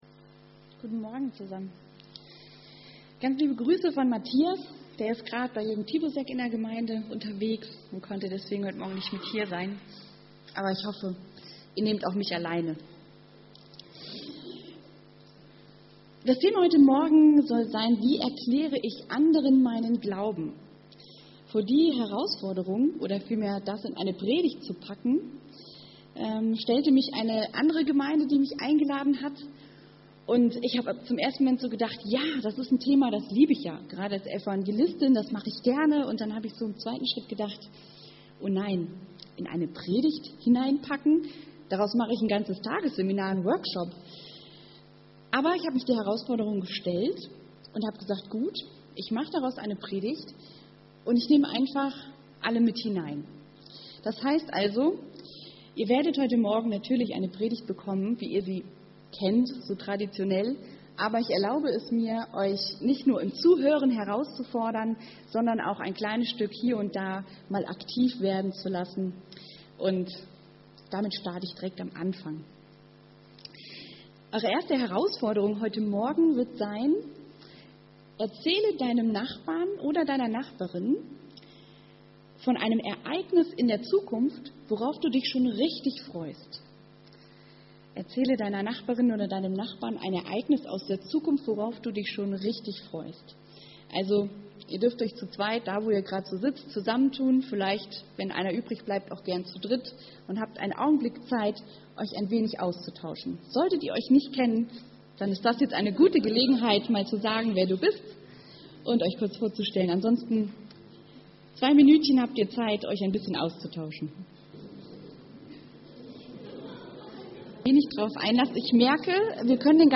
Evangelisch-freikirchliche Gemeinde Andernach - Predigt anhören